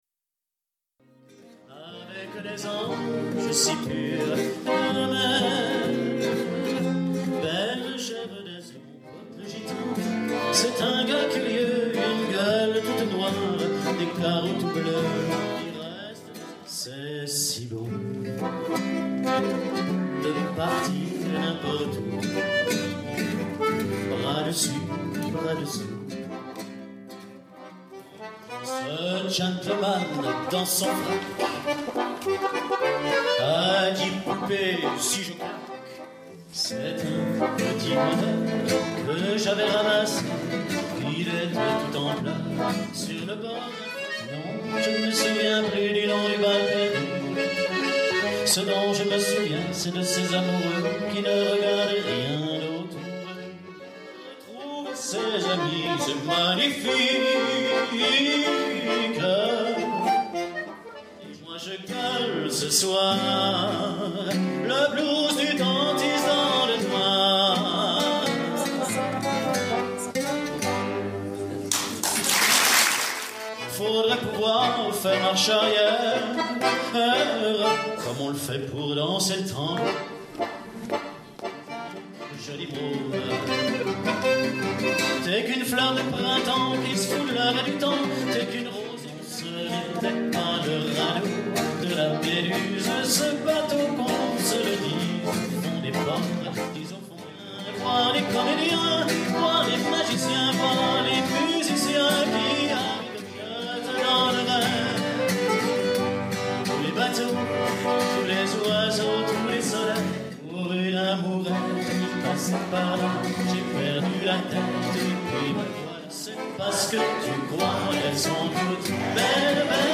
florilège de chansons des années 50/60